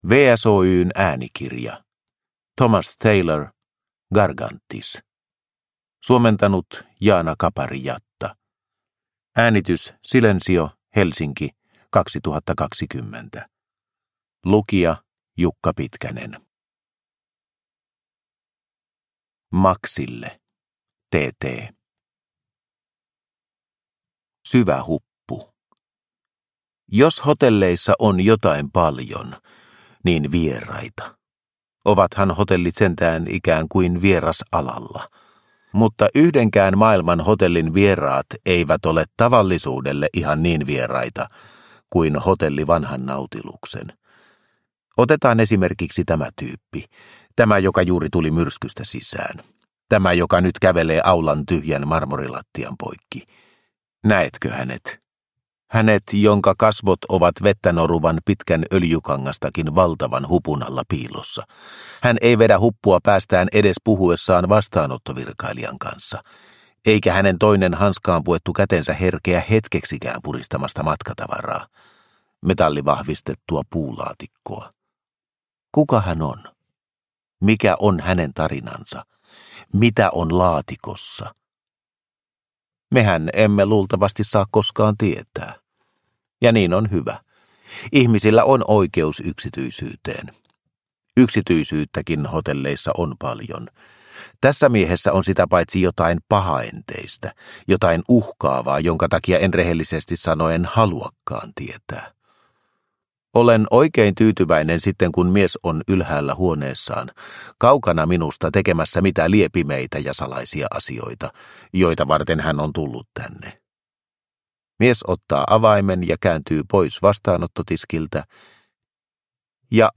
Gargantis – Ljudbok – Laddas ner